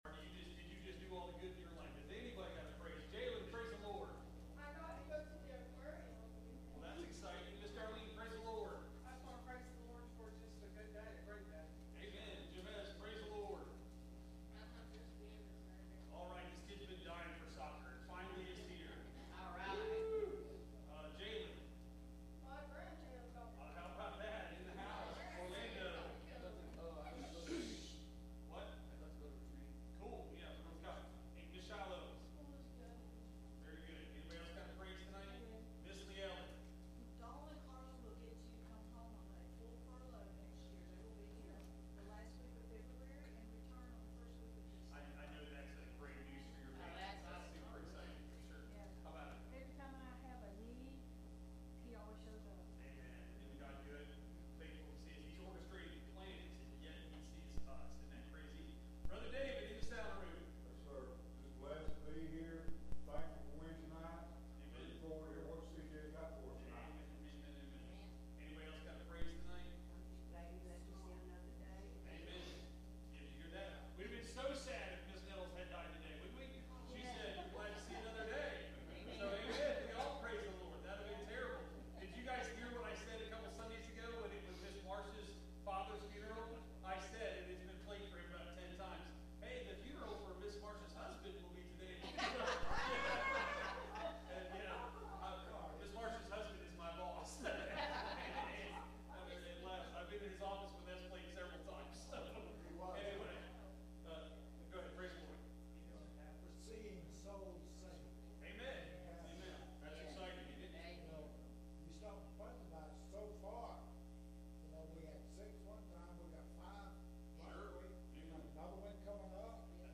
Series: Wednesday Night Service
2 Samuel 9:1 Service Type: Midweek Meeting « Wednesday Night Service When The Heat Is On